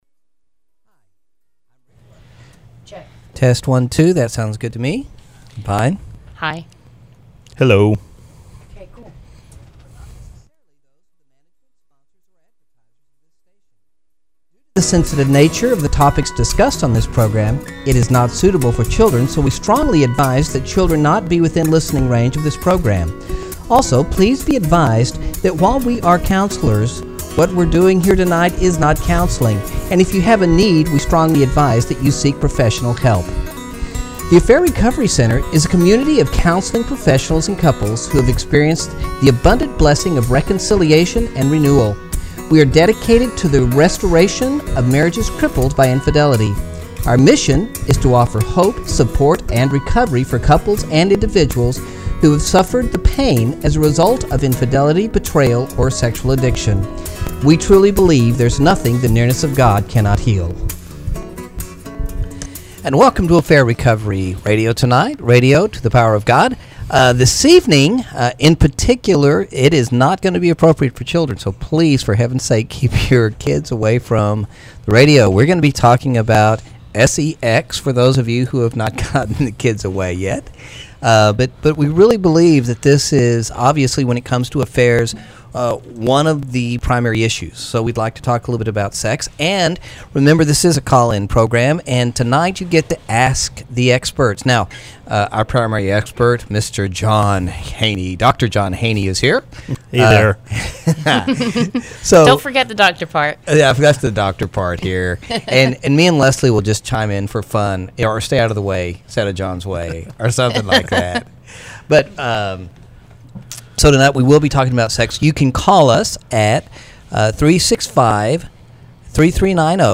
Several listeners called in with a variety of questions and comments for the team.